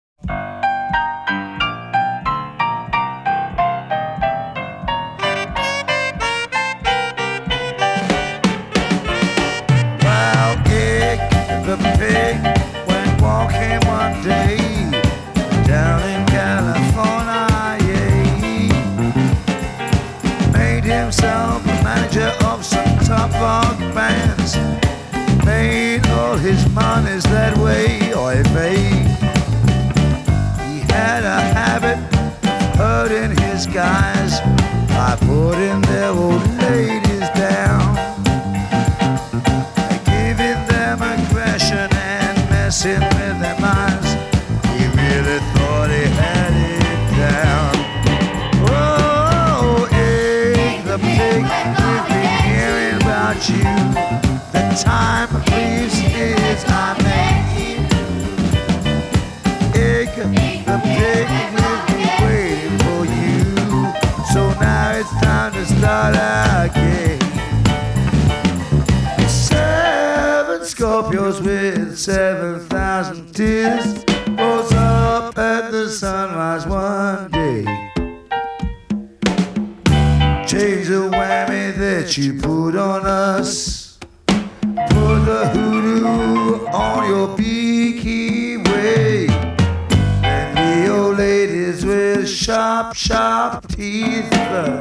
A fusion of jazz, rock and Afro influences